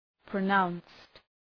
Προφορά
{prə’naʋnst}